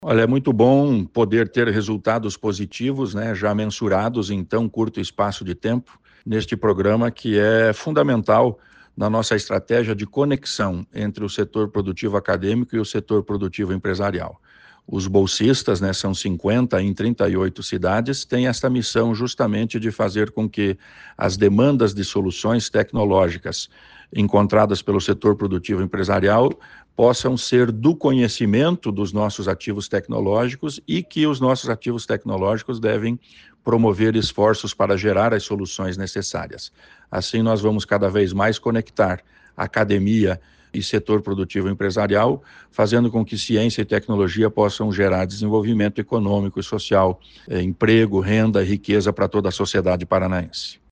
Sonora do secretário da Ciência, Tecnologia e Ensino Superior, Aldo Bona, sobre o Paraná ter mapeado 896 tecnologias desenvolvidas em diferentes instituições de ensino superior e de pesquisa